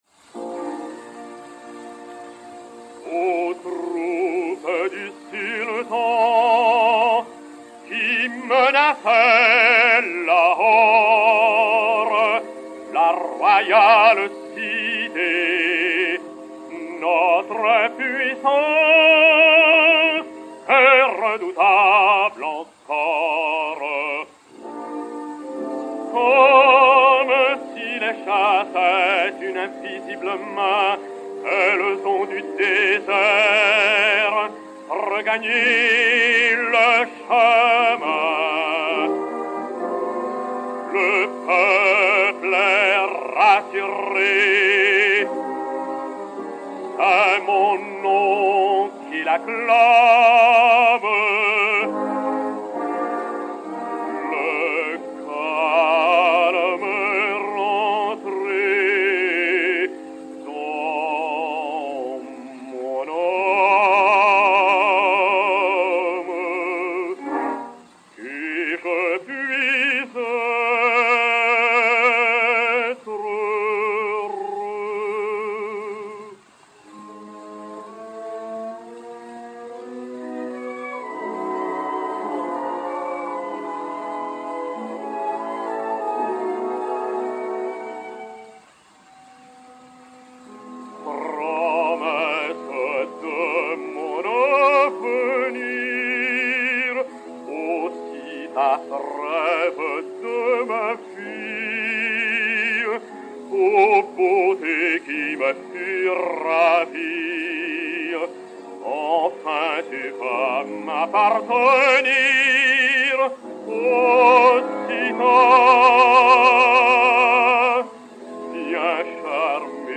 Arioso
et Orchestre